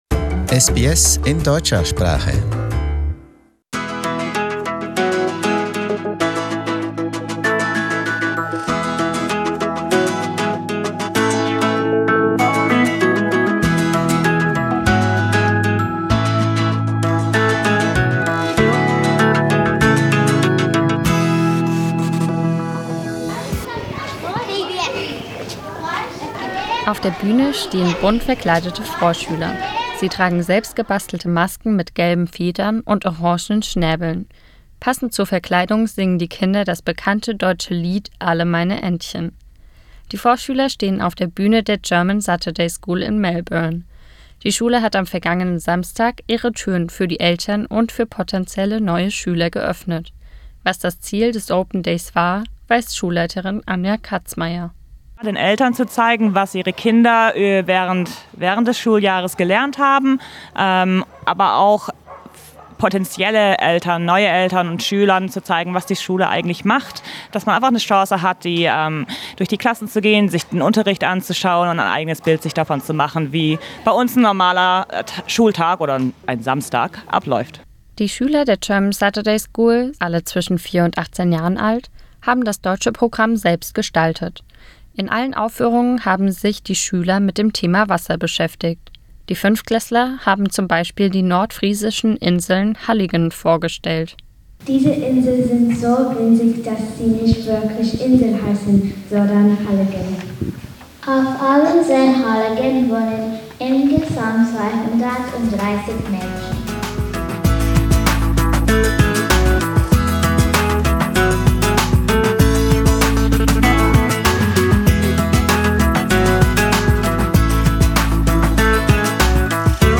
The German Saturday School in Melbourne opened its doors to parents and potential new students in mid-September. The program of the Open Day focused on the topic of water. All students of the German Saturday School were on stage. Listen to a lively report to find out more.